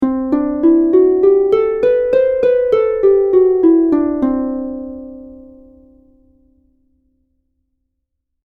Escala lidia
arpa escala modal música musical sintetizador